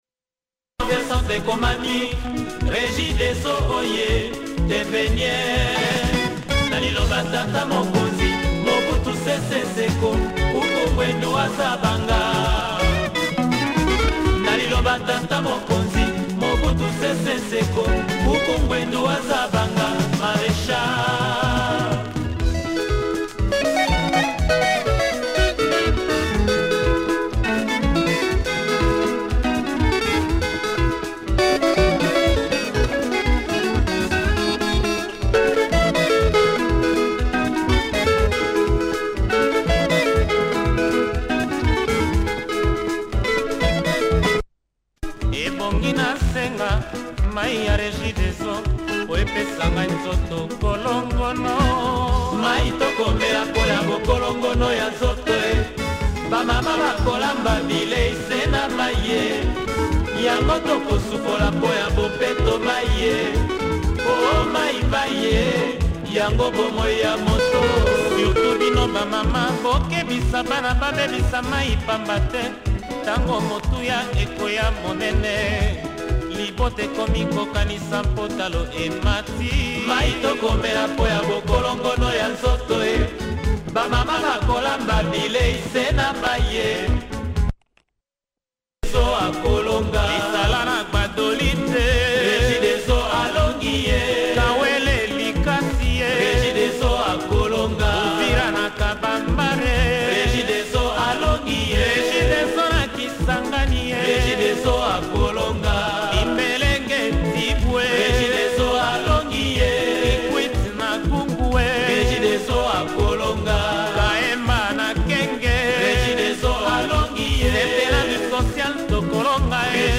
plays with some noise in the start